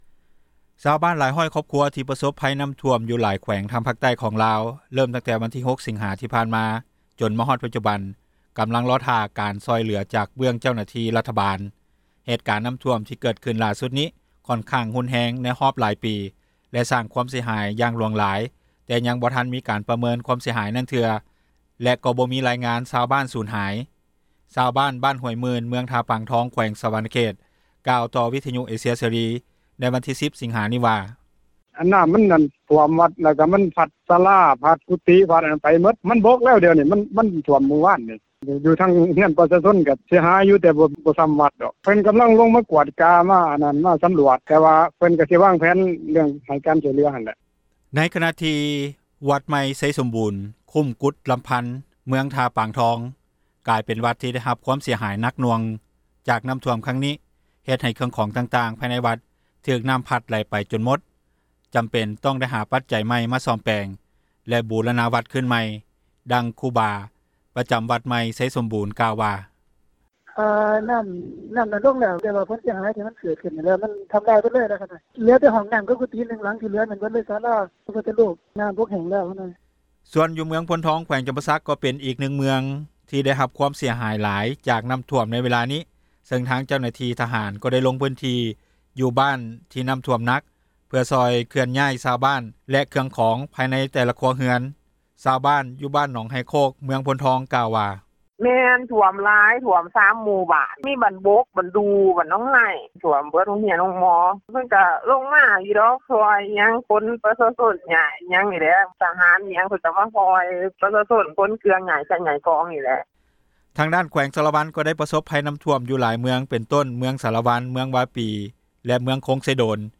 ຊາວບ້ານຢູ່ບ້ານ ໜອງໄຮໂຄກ ເມືອງໂພນທອງກ່າວວ່າ:
ຊາວບ້ານເມືອງຄົງເຊໂດນກ່າວວ່າ:
ເຈົ້າໜ້າທີ່ ຜແນກແຮງງານ ແລະສວັດດີການສັງຄົມ ແຂວງສວັນນະເຂດ ກ່າວວ່າ: